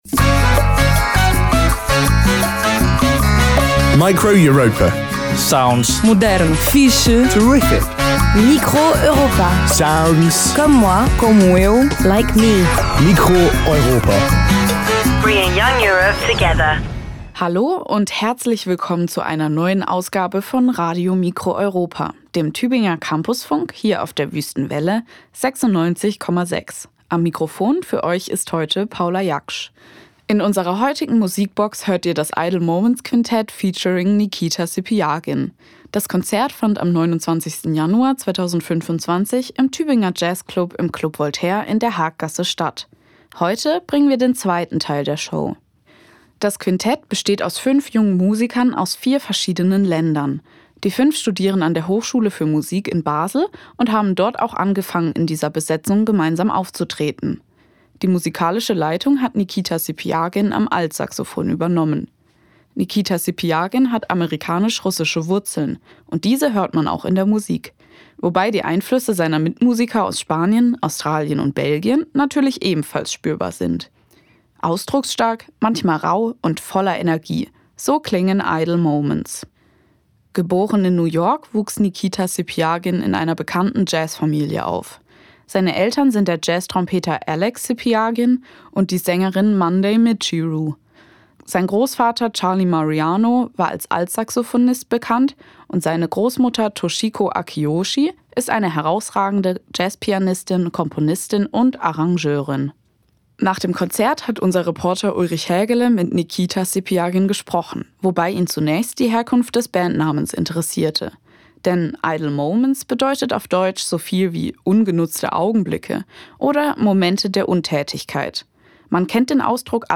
Altsaxofon
Trompete
Nord-Piano
Bass
Schlagzeug
Live-Aufzeichnung, geschnitten